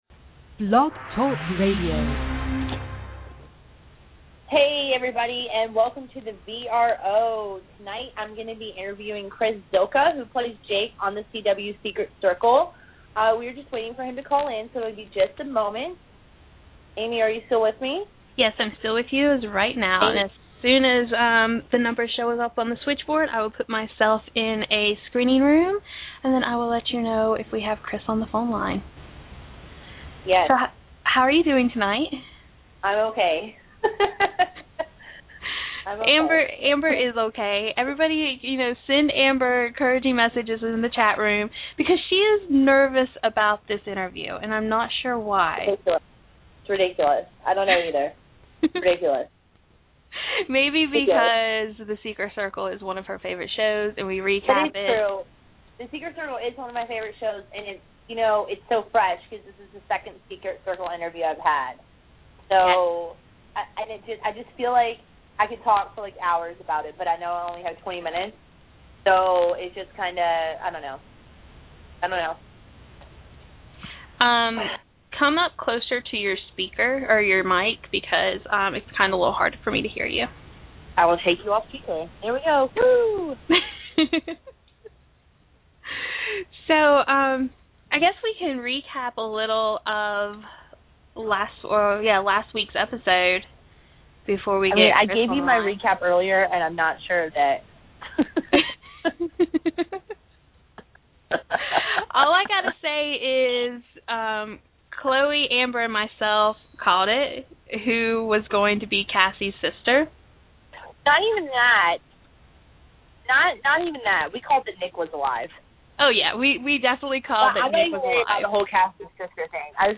Chris Zylka Interview